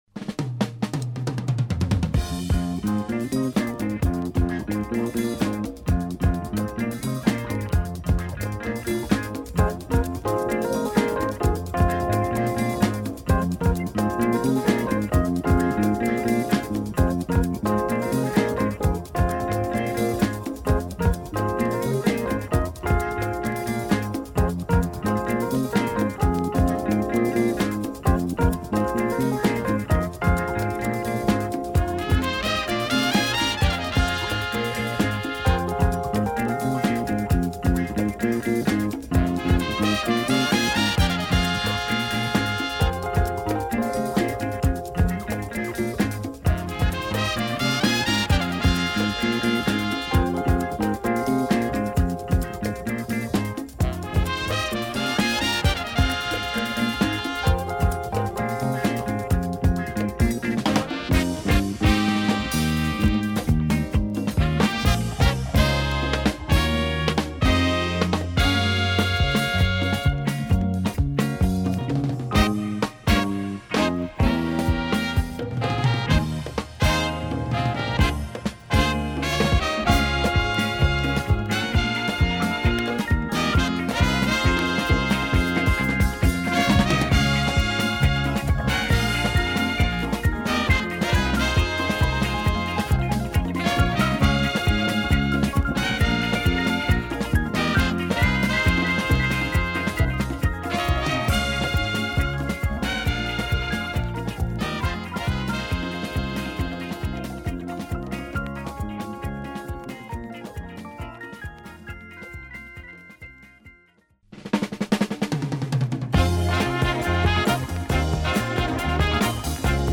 Rare West Indian funk and jazz album.
Fender Rhodes